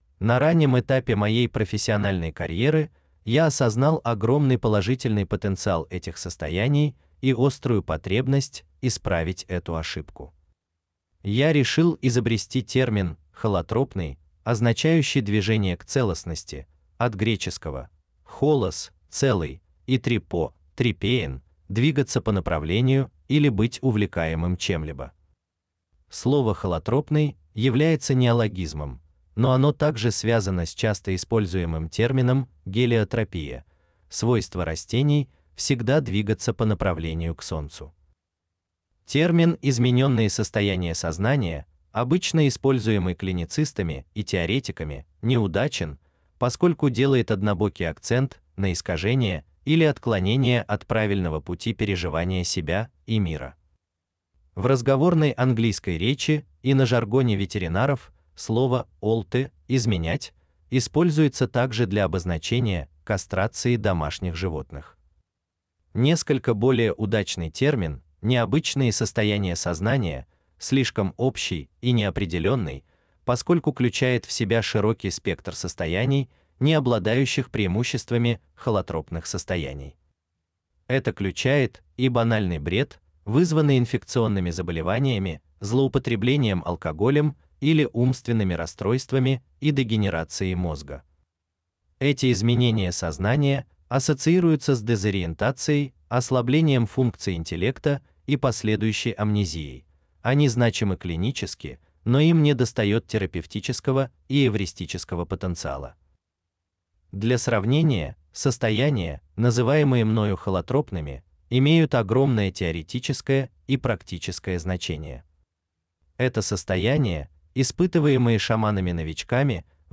Книга озвучена с помощью искусственного интеллекта.